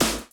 JB SNARE 2.wav